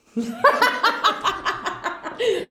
LAUGHTER.wav